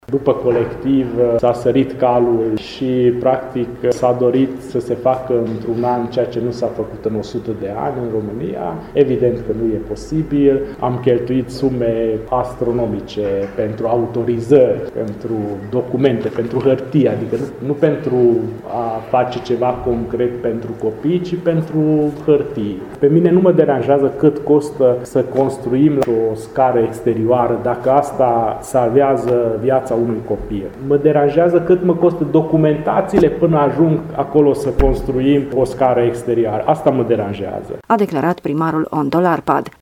Primarul municipiului Sfântu Gheorghe, Antal Arpad a declarat că a contestat amenda, menţionând, în context, că după drama petrecută în urmă cu aproape trei ani în Clubul “Colectiv” din Bucureşti, unde au murit câteva zeci de persoane, autorităţile centrale “au sărit calul” în ce priveşte autoritaţiile pentru siguranţă la incendii, punând reprezentanţii administraţiilor locale într-o mare dificultate financiară.